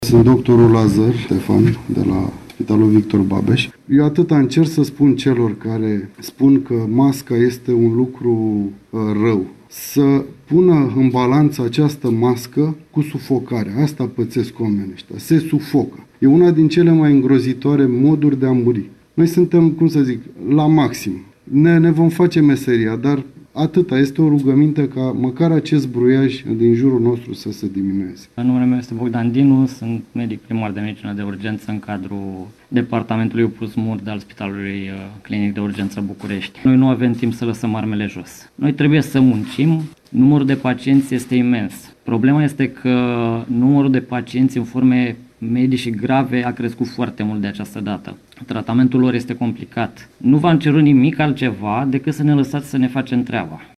Câţiva medici le-au transmis marți, într-o conferinţă de presă organizată de Ministerul Sănătăţii, un mesaj celor care au protestat față de restricțiile antiepidemice.